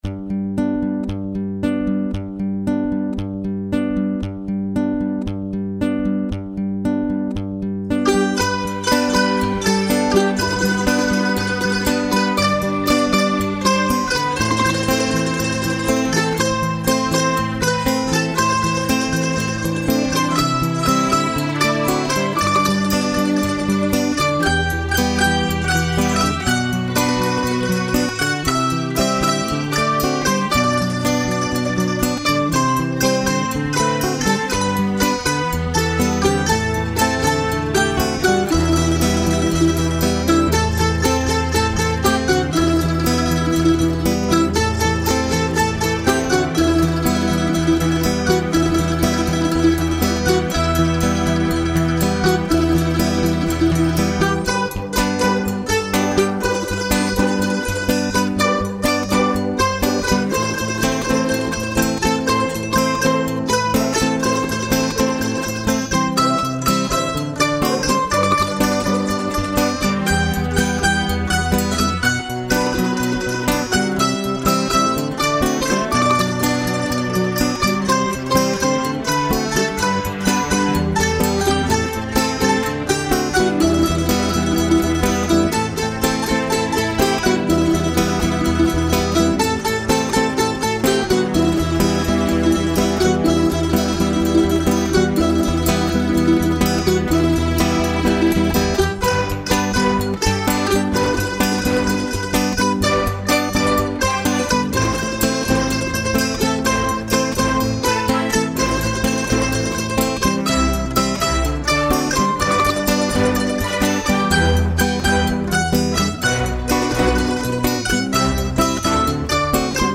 02:44:00   Clássica